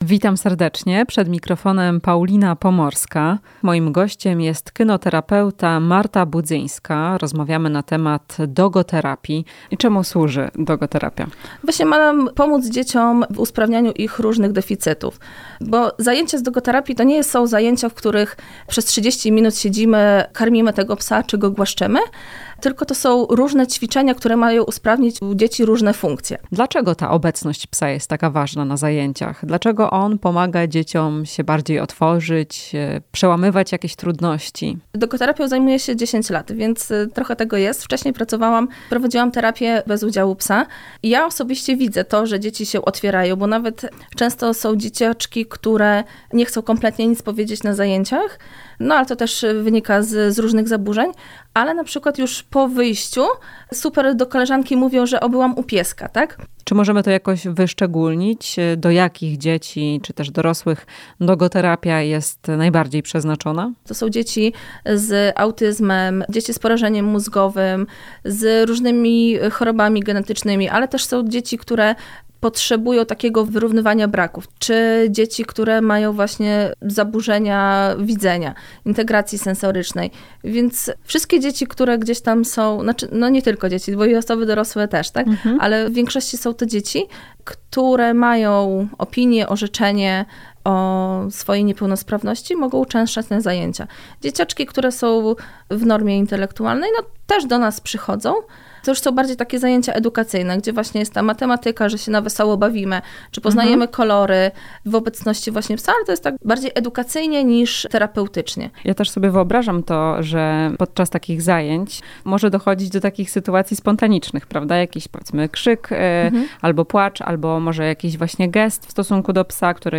Rozmowa z kynoterapeutą